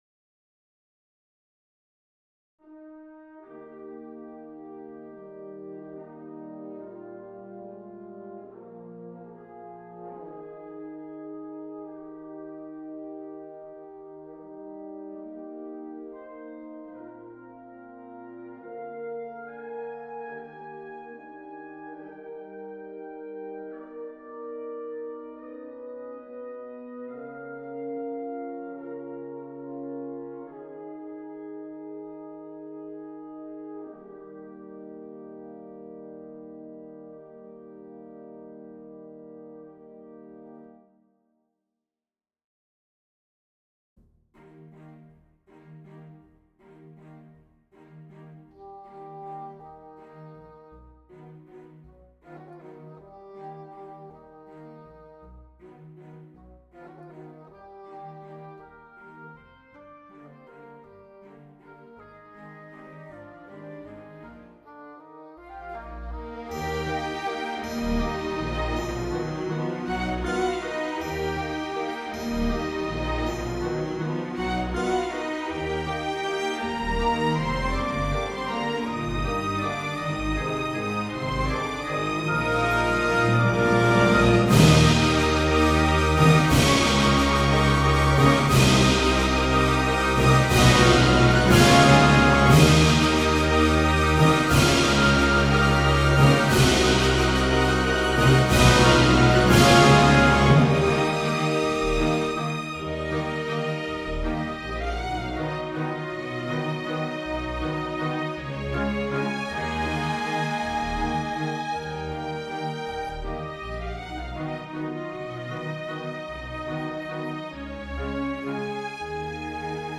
Adagio maestoso - Allegro molto - Vivace con spirito - Andante cantabile ▶ 0 plays previous > next